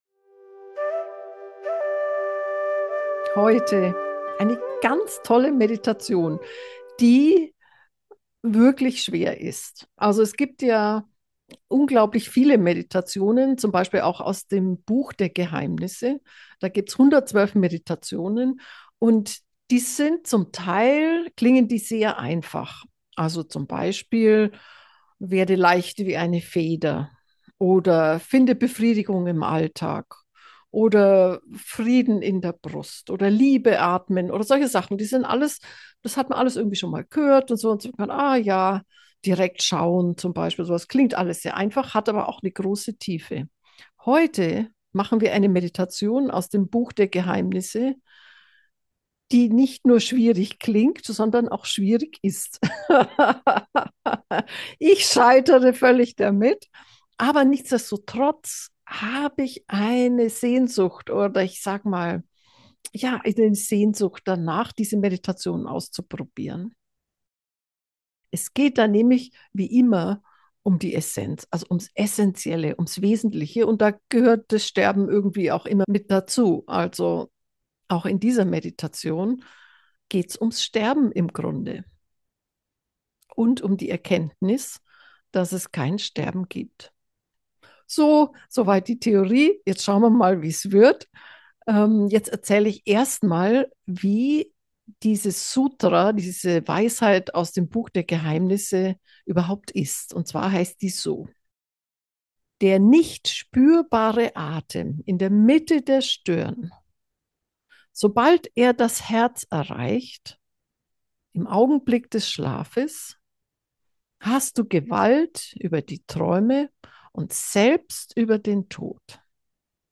Folge 262: Das dritte Auge und das Prana – eine Einschlafmeditation